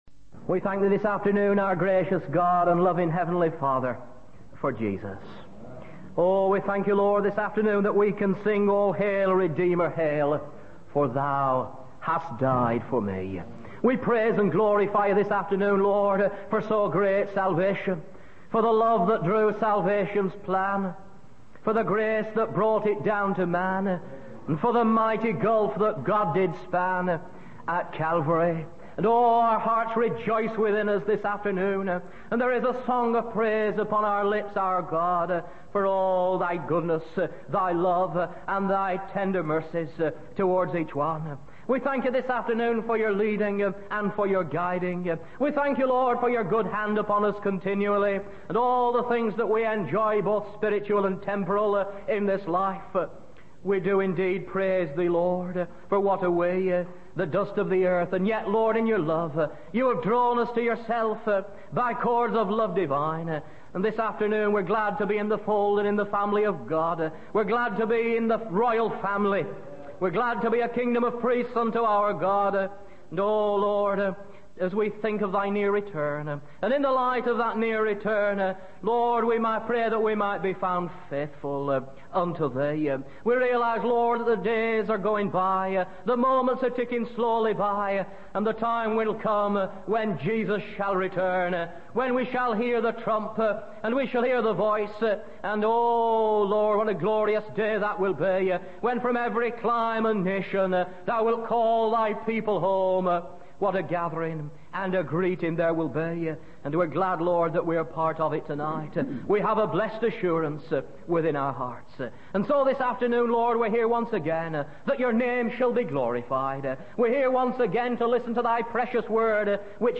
In this sermon, the preacher emphasizes the importance of living in anticipation of Jesus' return. He highlights the four accounts of God's riches mentioned in the Bible: his goodness, wisdom, grace, and glory, which provide for all our needs. The preacher encourages believers to live holy lives, testing and discerning what is excellent, in preparation for the day of Christ.